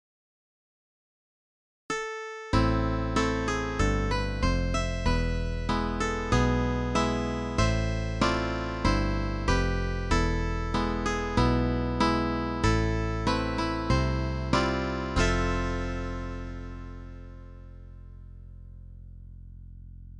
Celtic
Am